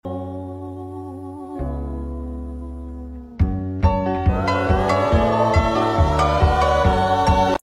The lilacs, the bees, the sound effects free download
the Mp3 Sound Effect The lilacs, the bees, the breeze…